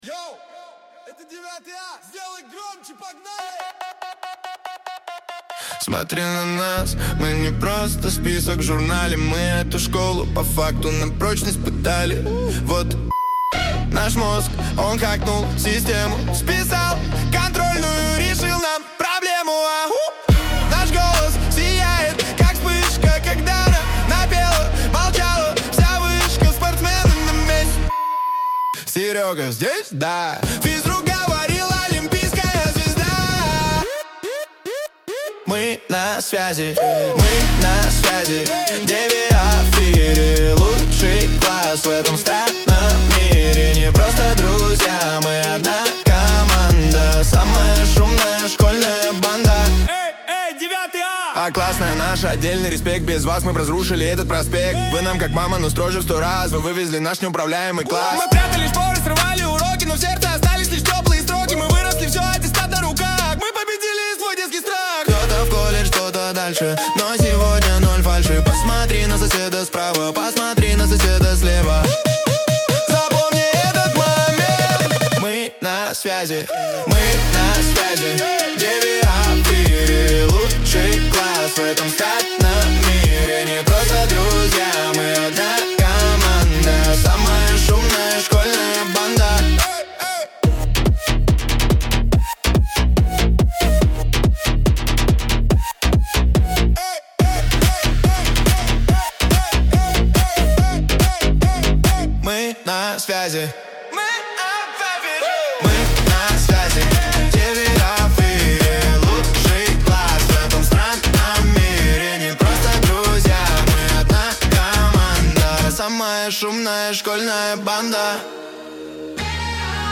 • Тяжелый бас и быстрый флоу (140 BPM)
🎧 Слушать пример (Modern Trap, 140 BPM):